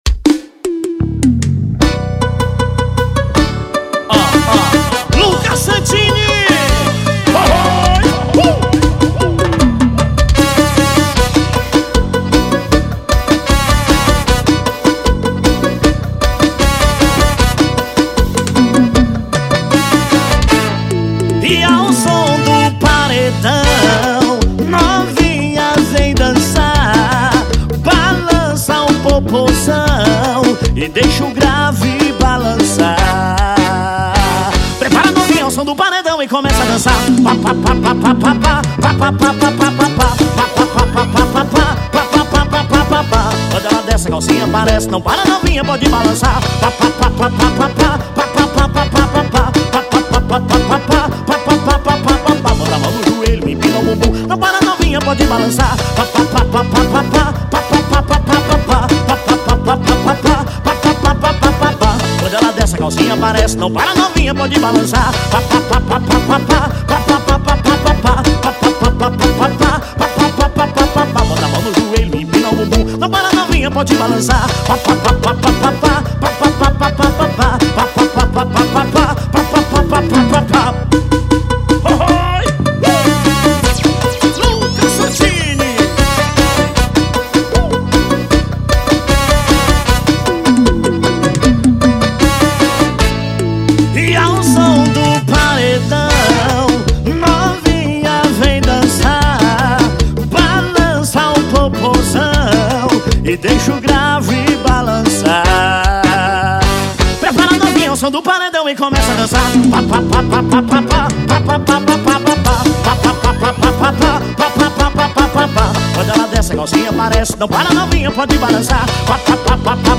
Forró de paredão